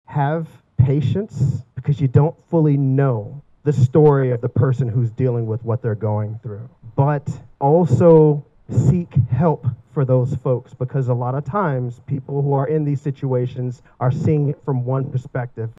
A Silent Witness Ceremony was held at the Riley County Courthouse Plaza Wednesday over the lunch hour.
RCPD Director Brian Peete addresses the gathering Wednesday outside the County Attorney’s Office.